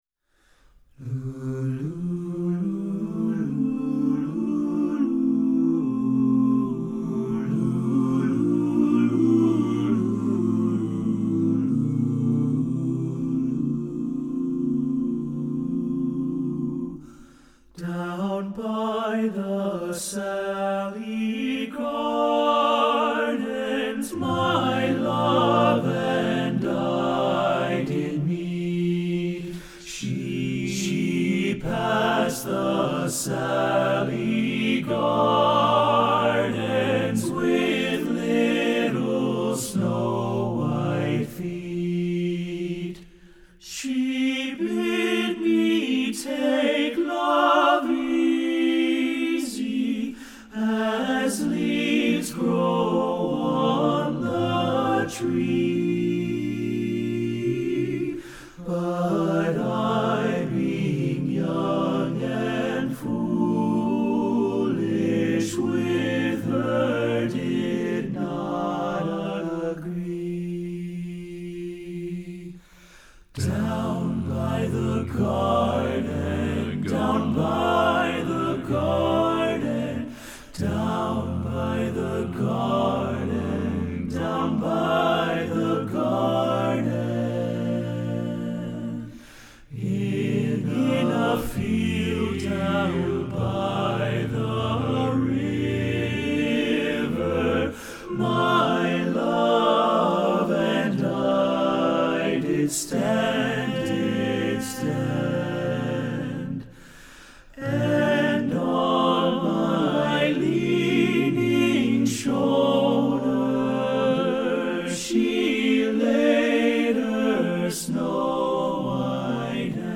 Composer: Traditional Irish Melody
Voicing: TTBB a cappella